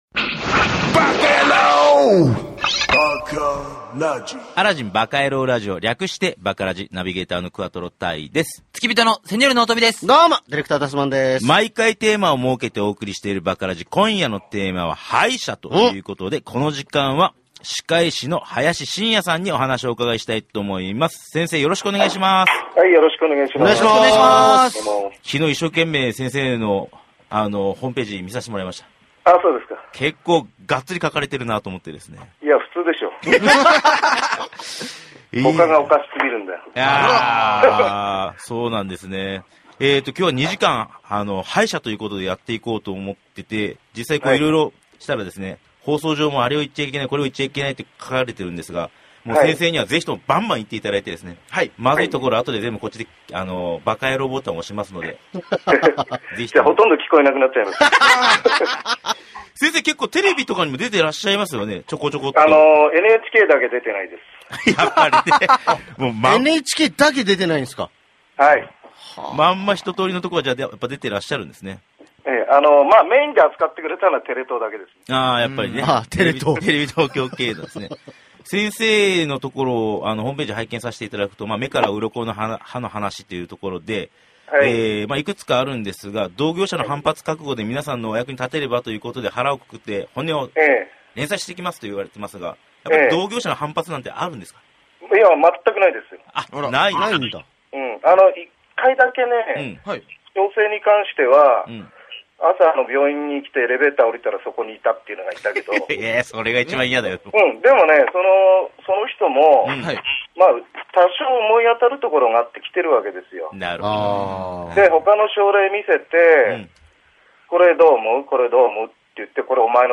11月10日に九州地方で放送された『Aladdin バカヤロー Radio !』に電話出演した録音CDが届きました。
スタジオのパーソナリティや出演者も爆笑したり、感心してうなったりと、ほかでは聞くことの出来ないP音ぎりぎりの「歯医者」の本音トークに大いに盛り上がりました。べらんめい口調ですが、きっと皆様の歯と口の健康のお役に立つと自負しています。